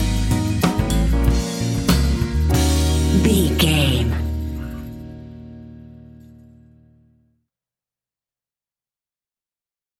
Epic / Action
Fast paced
In-crescendo
Uplifting
Ionian/Major
D♯